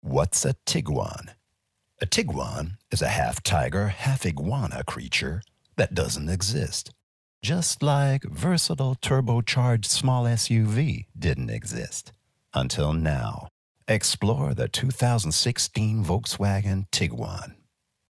US English (Werbung) VW Tiguan